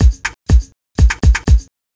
Royalty Free Loops Library: Drumloops